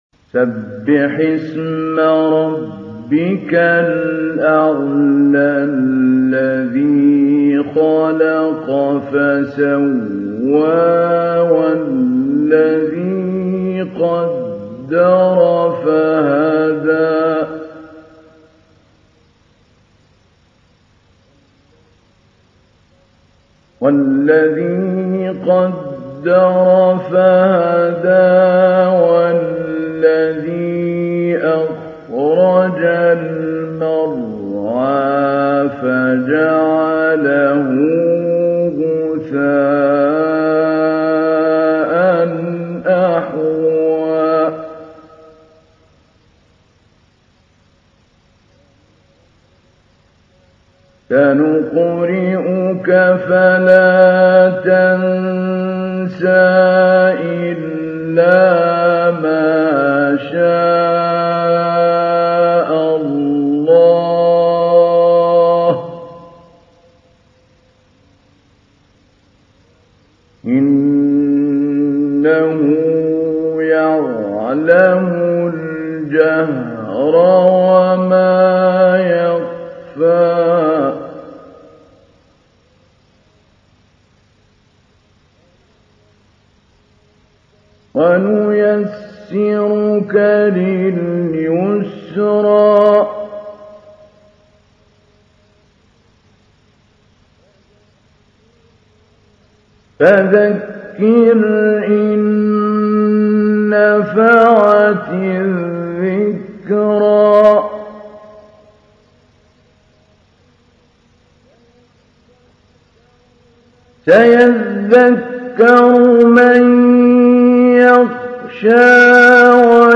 تحميل : 87. سورة الأعلى / القارئ محمود علي البنا / القرآن الكريم / موقع يا حسين